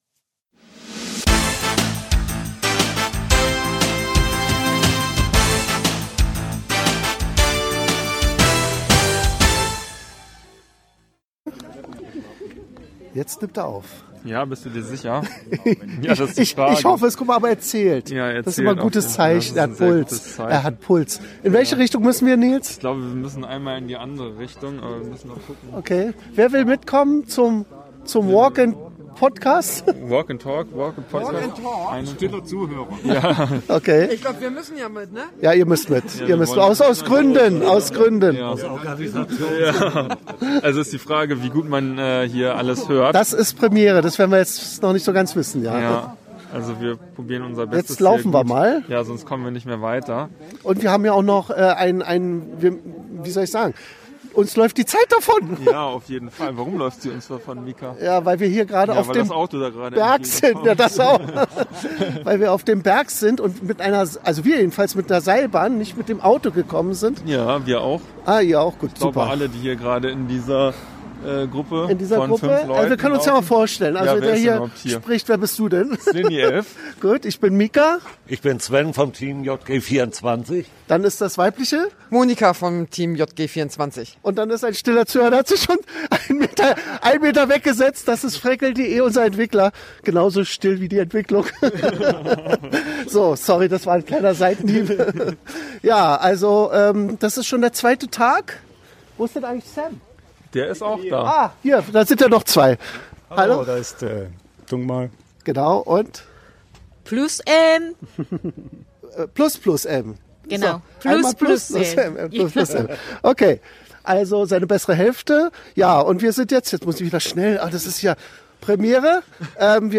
(a) - Live vom 12. OC-HQ-Event auf der Suche nach dem verschwundenen Wanderer ~ OC Talk (MP3 Audio) Podcast